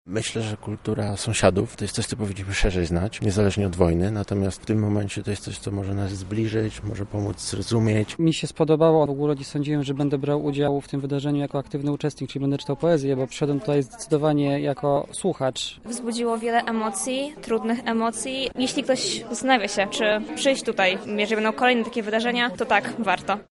Uczestnicy wydarzenia